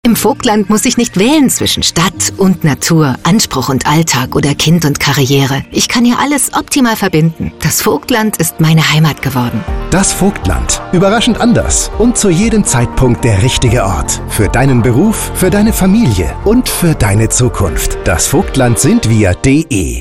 Radiospot auf BR 3